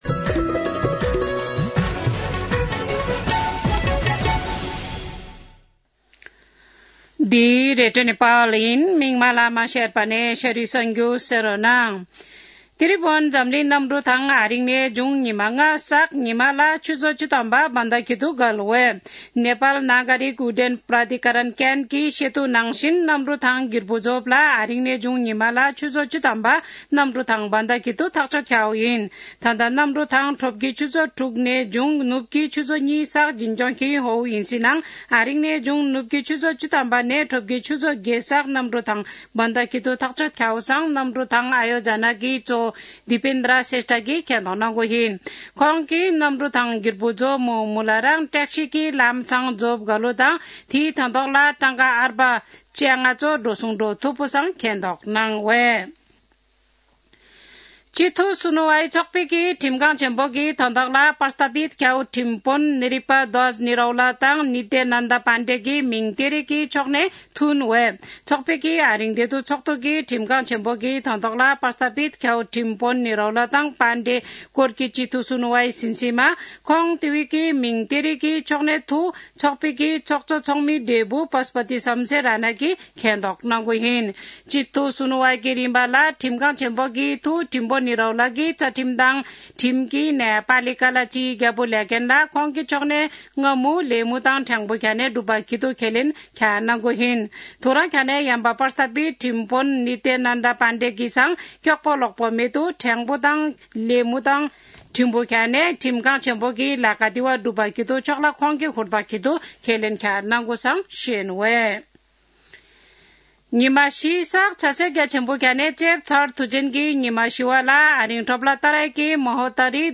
शेर्पा भाषाको समाचार : २४ कार्तिक , २०८१
Sherpa-News-23.mp3